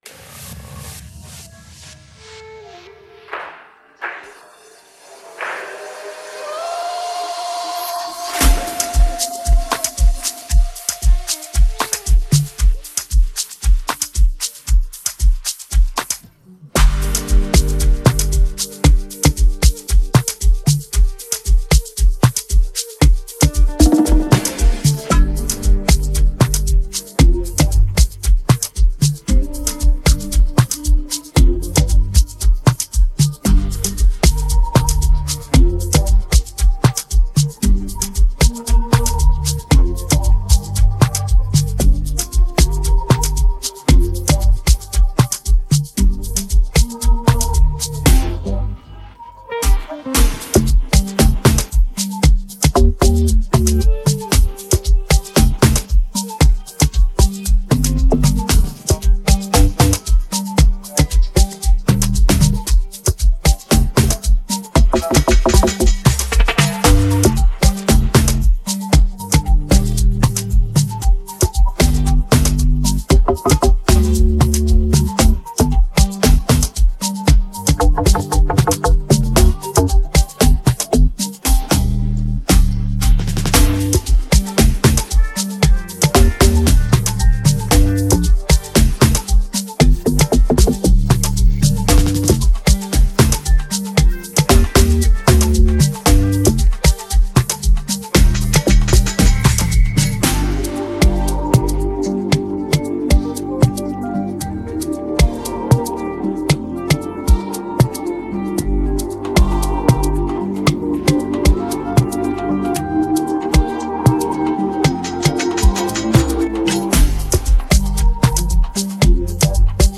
amapiano Instrumental